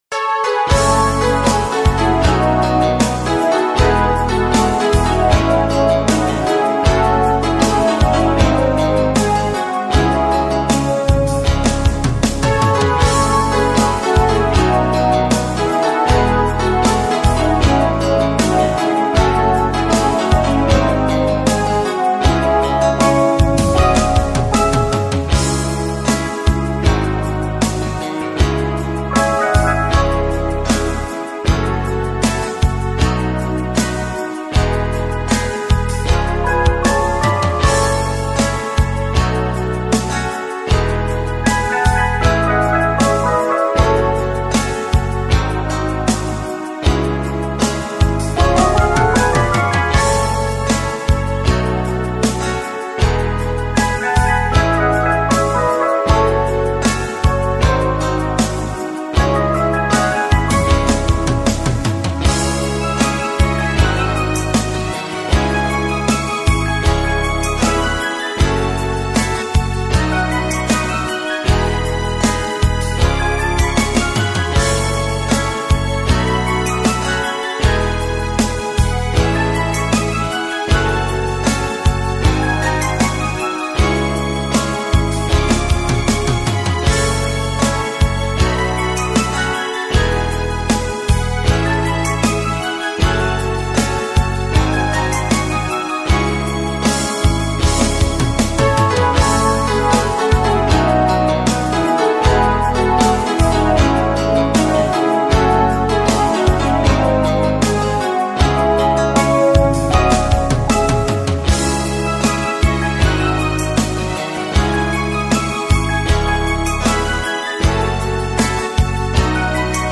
инструментальная версия
без слов